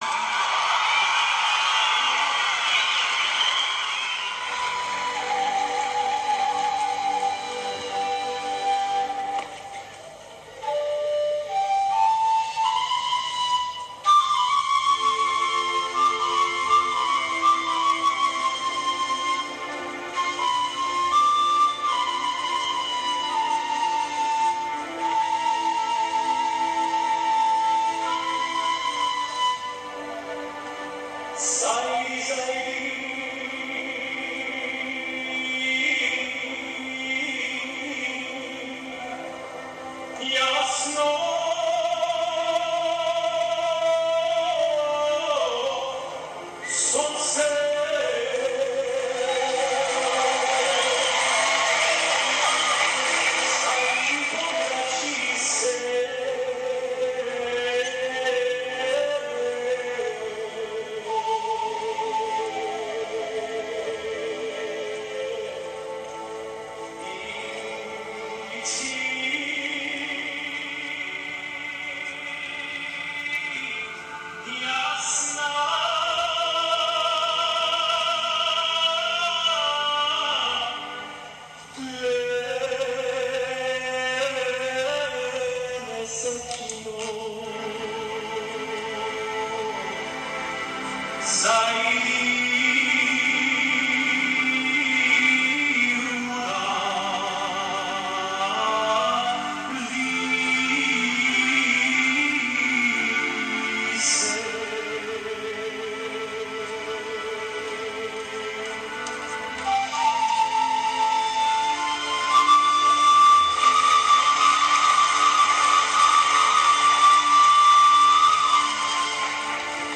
More than 1,500 guests at The State Theatre, Sydney, attending the 50th anniversary of MCAA Ilinden were welcomed with traditional pogacha. Feel some of the atmosphere and the audience ovation here